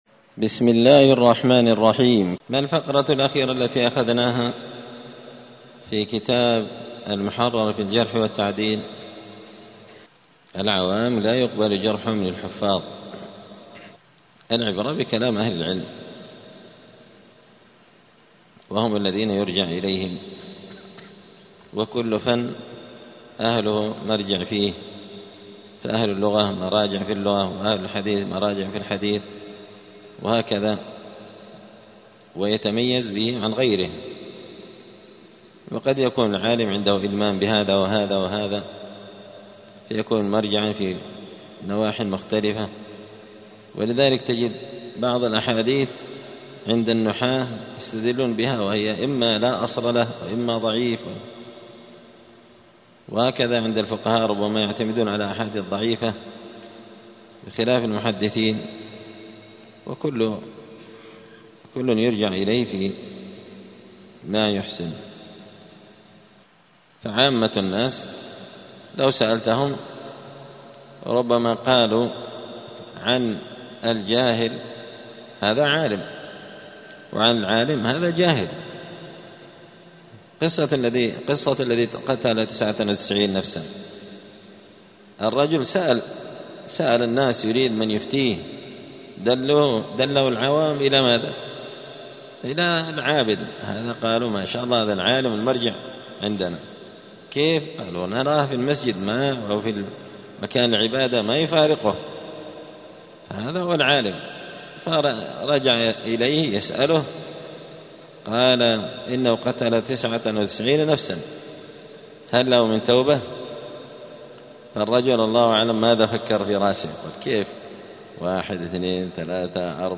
*الدرس التاسع و الستون (69) باب لا يلزم النسيان اختلال الضبط*
دار الحديث السلفية بمسجد الفرقان قشن المهرة اليمن